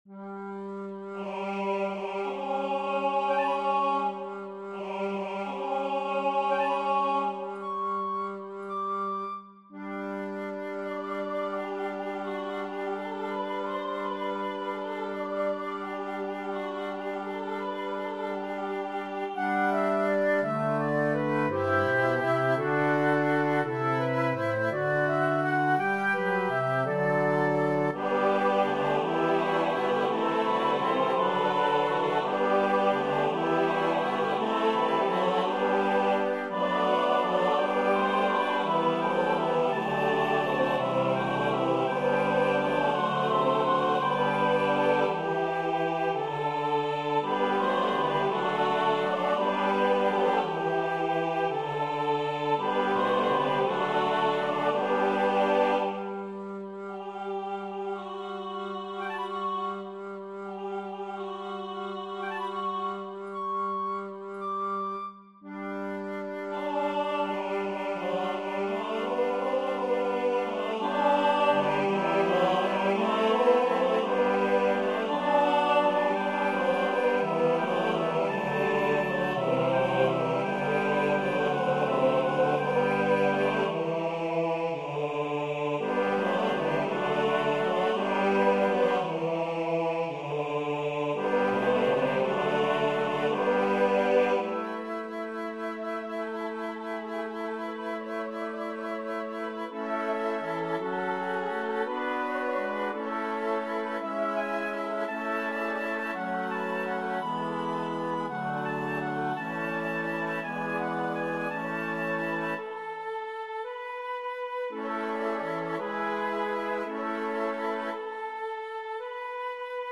carol
moderate SATB with organ or piano accompaniment.